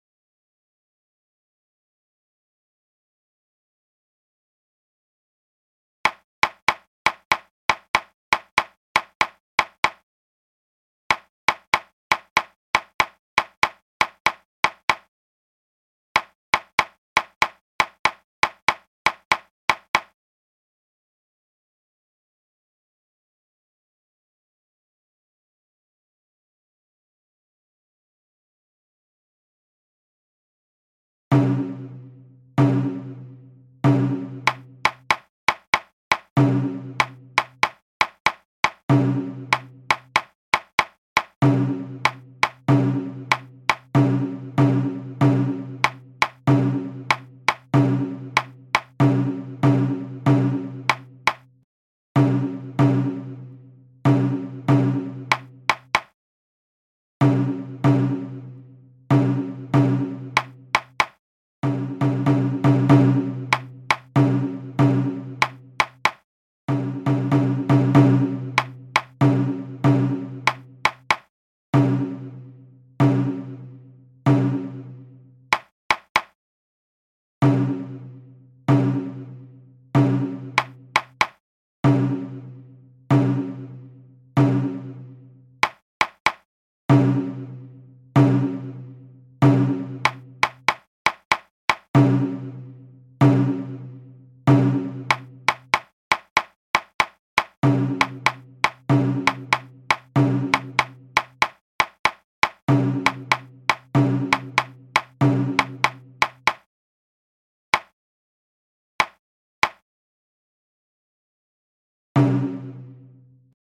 hiryu-sandan-gaeshi-group_a+group_b+shime-120bpm-1000ms.mp3 (1.4M - updated 10 months, 2 weeks ago)